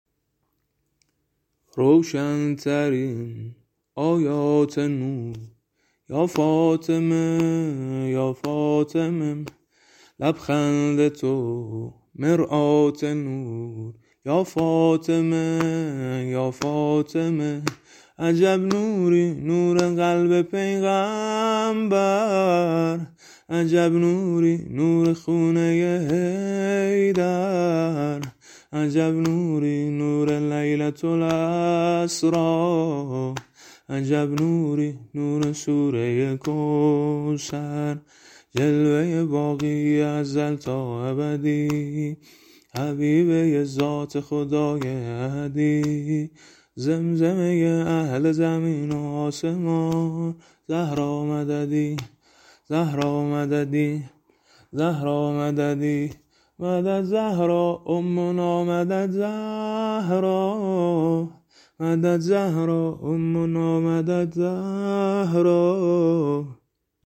فاطمیه ۱۴۴۶ ه.ق